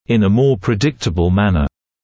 [ɪn ə mɔː prɪ’dɪktəbl ‘mænə][ин э моː при’диктэбл ‘мэнэ]более предсказуемым образом